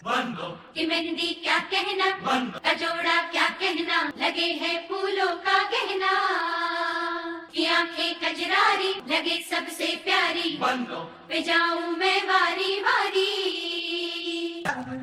Bollywood Song
Chorous,